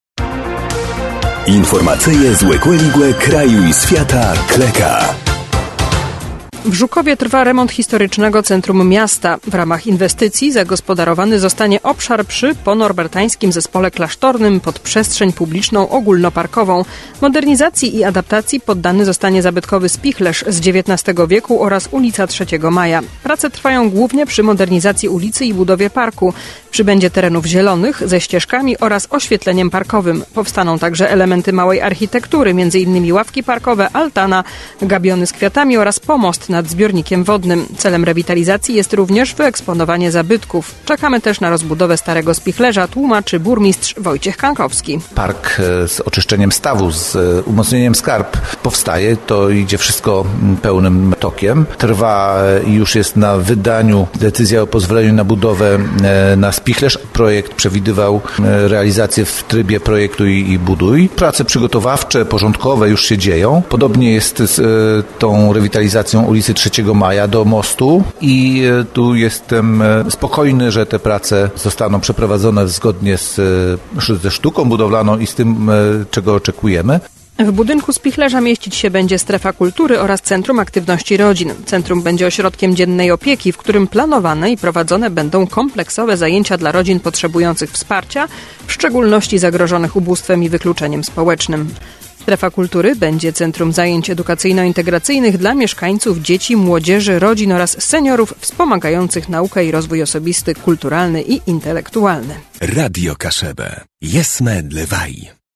– Czekamy też na rozbudowę starego spichlerza – tłumaczy burmistrz Wojciech Kankowski.